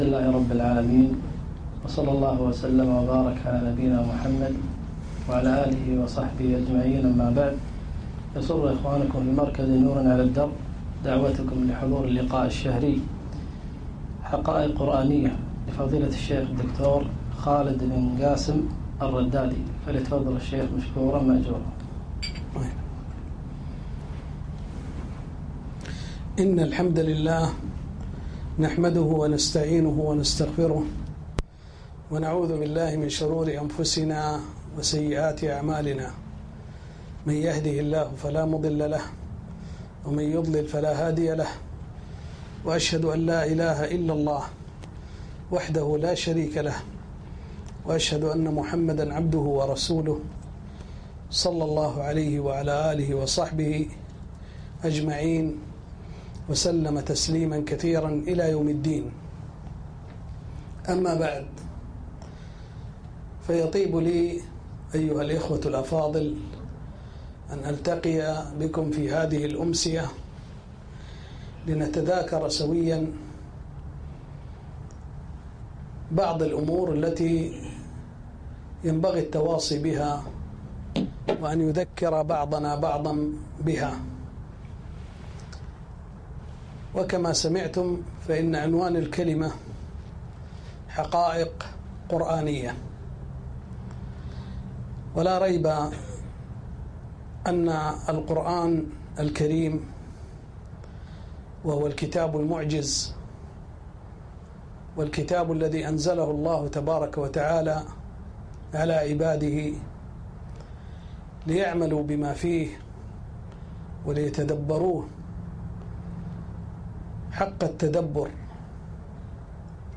محاضرة - حقائق قرآنية